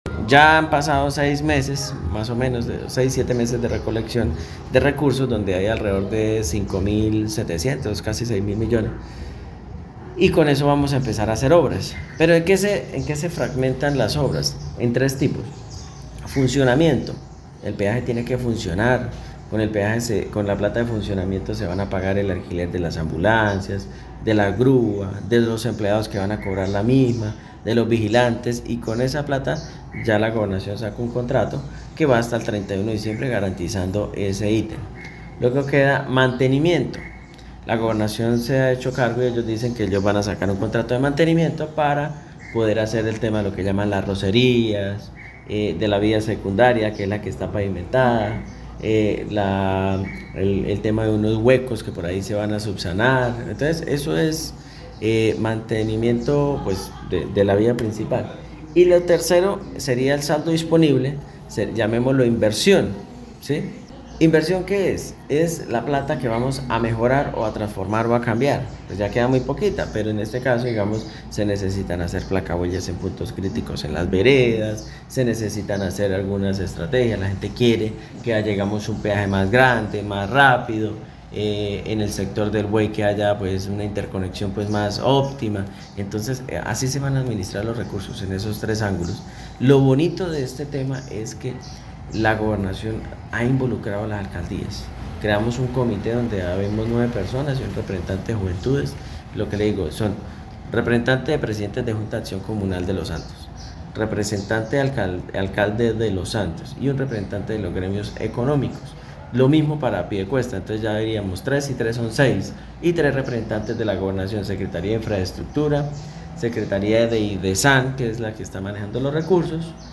Diego Armando Mendoza, alcalde de Los Santos